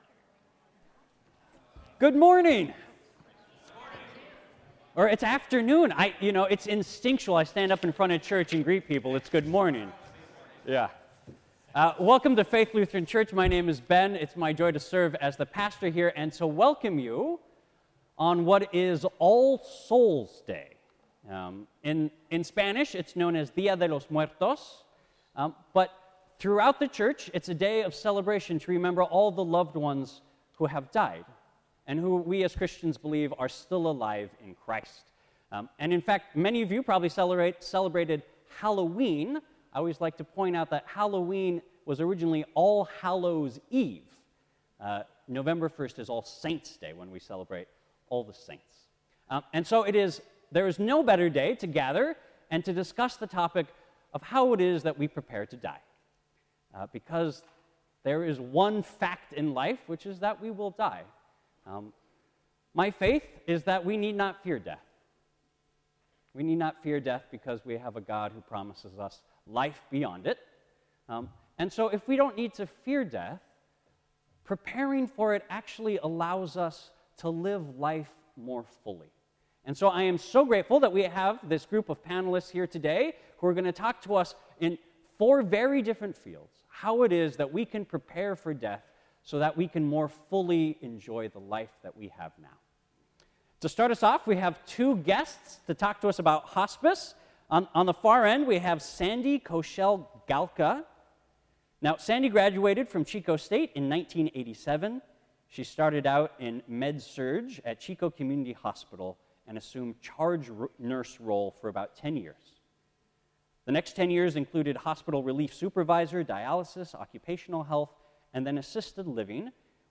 Sacred Conversations hosts a live panel, including a chaplain, a lawyer, a hospice worker, and a funeral director about the best practices for preparing for death spiritually, legally, emotionally, and practically. We will feature various practical ways of preparing for death such as planning your funeral service, preparing an advance directive, writing an obituary, creating a memorial altar for loved ones who have died, and reflecting on what you would like to do with your life before you join those loved ones.